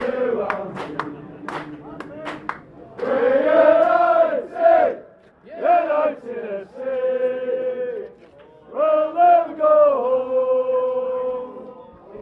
It was recorded by us as we stood among the supporters of FC United of Manchester at their game at Stourbridge, which finished 2-1 to Stourbridge in the Evo-Stik Premier League.